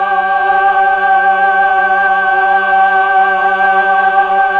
Index of /90_sSampleCDs/Keyboards of The 60's and 70's - CD1/VOX_Melotron Vox/VOX_Tron Choir